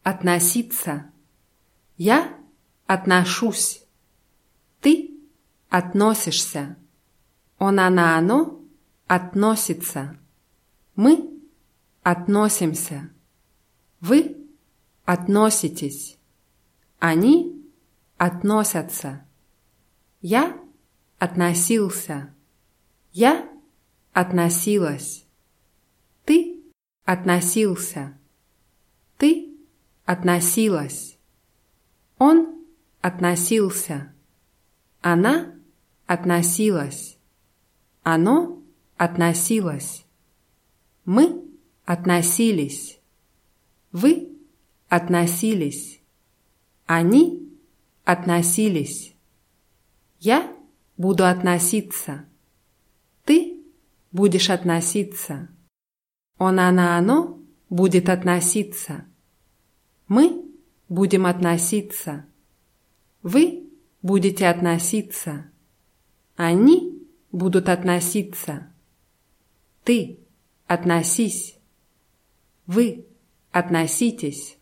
относиться [atnaßʲítsa]